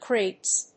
/krets(米国英語), kreɪts(英国英語)/